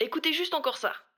VO_ALL_Interjection_06.ogg